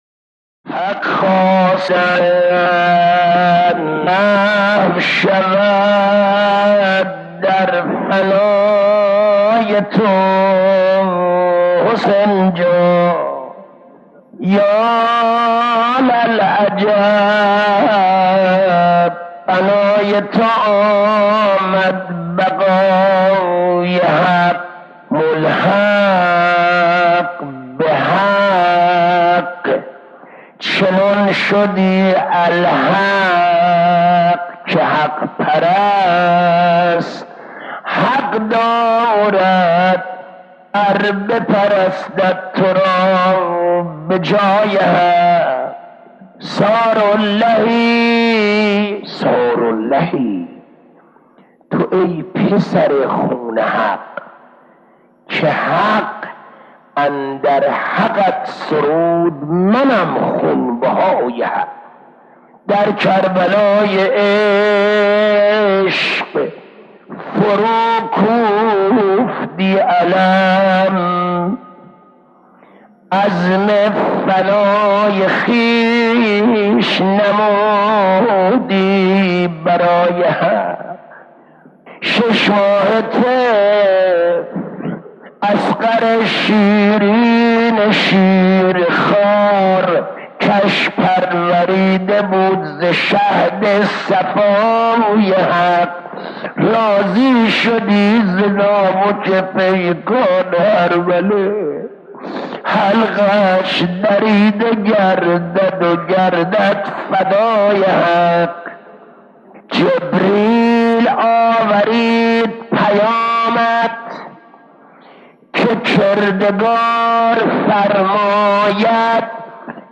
مدح و مرثیه‌خوانی درباره حضرت اباعبدالله